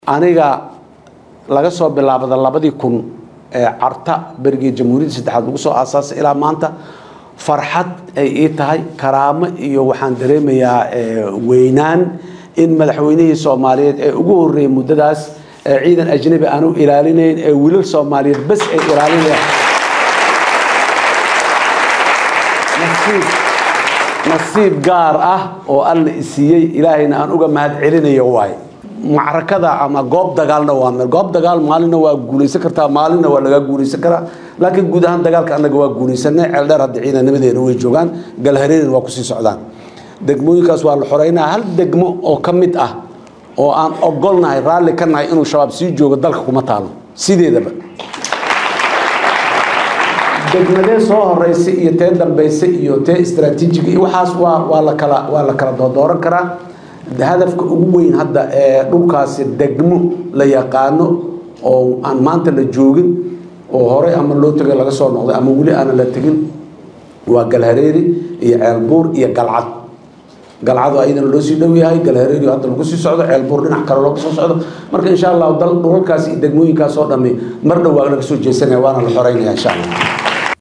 Madaxweynaha Soomaaliya Mudane Xasan Sheekh Maxamuud ayaa ka qeyb-galay dood-furan oo si toos ah ay shacabka Soomaaliyeed ugu weydiinayeen su’aalahooda la xiriira xaaladaha guud ee wadanka.